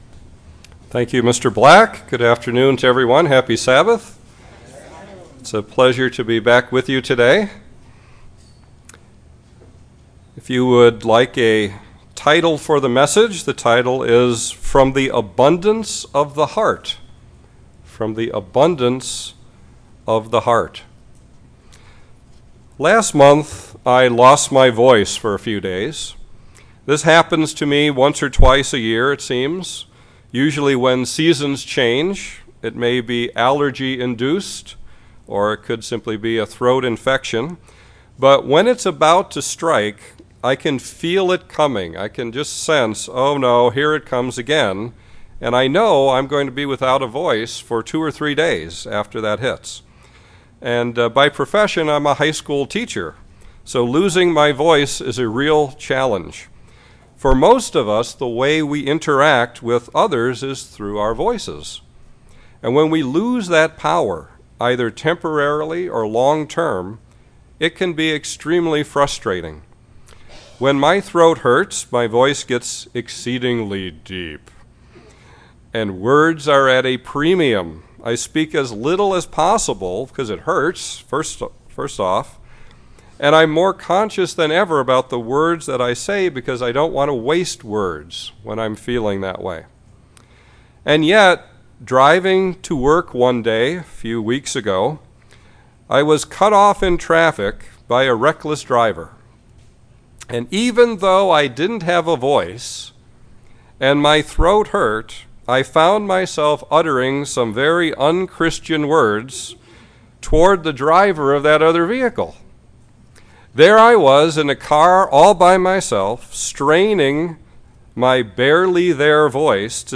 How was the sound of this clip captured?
Given in Chicago, IL Beloit, WI